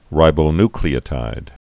(rībō-nklē-ə-tīd, -ny-)